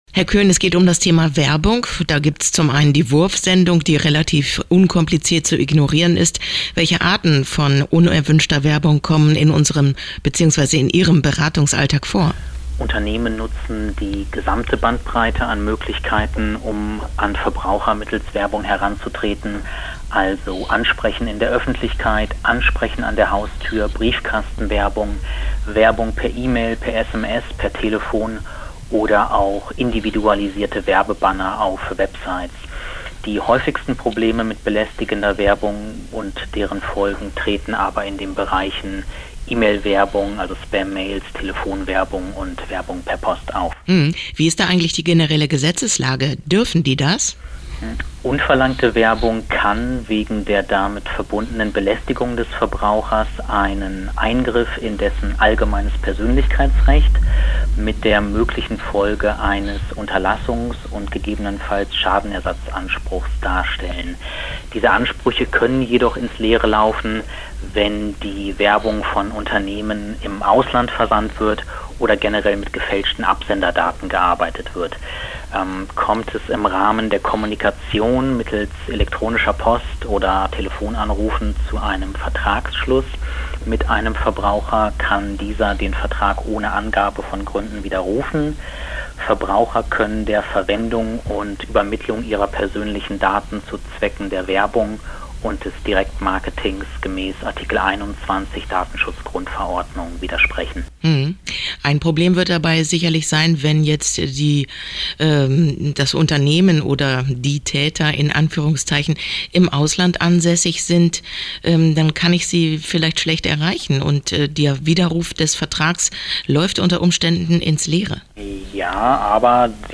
Interview-Verbraucher-25-03-Werbung.mp3